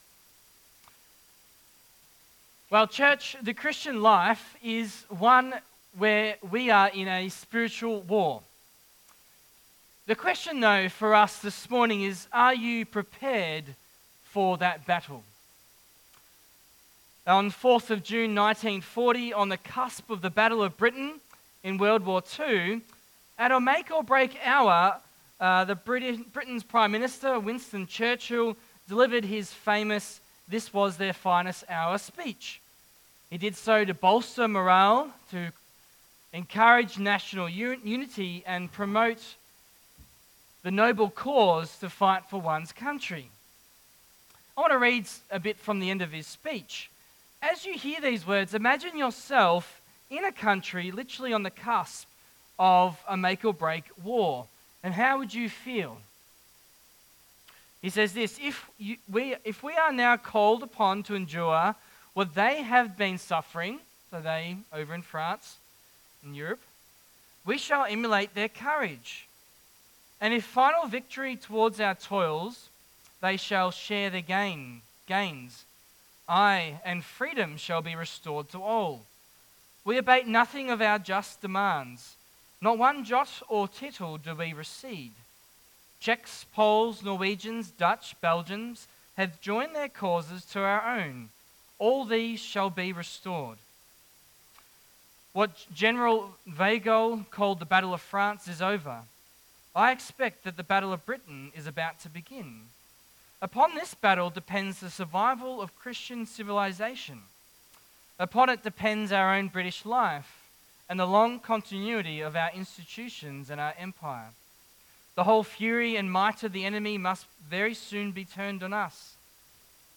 Passage: Joshua 1:1-18 Service Type: AM